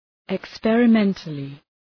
Shkrimi fonetik{ık,sperə’mentlı}